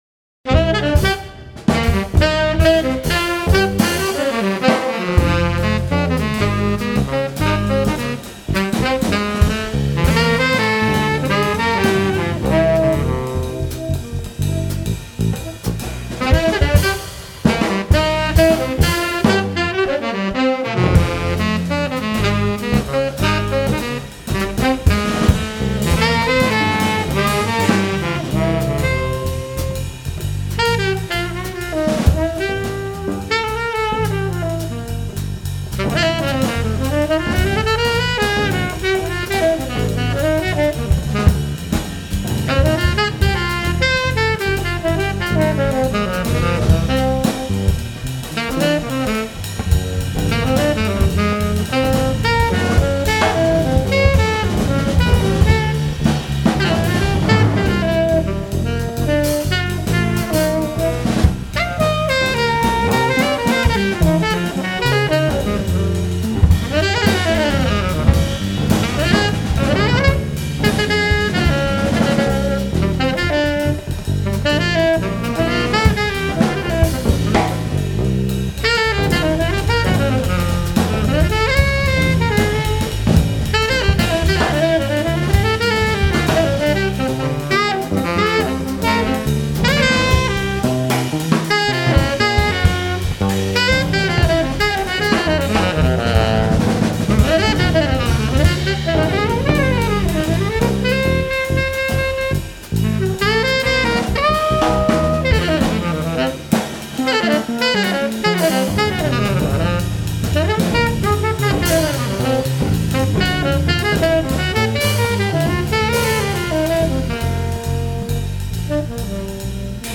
drums
bass
File: Jazz/Imp/Funk